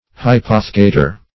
Search Result for " hypothecator" : The Collaborative International Dictionary of English v.0.48: Hypothecator \Hy*poth"e*ca`tor\, n. (Law) One who hypothecates or pledges anything as security for the repayment of money borrowed.